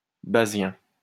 Bazien (French pronunciation: [bazjɛ̃]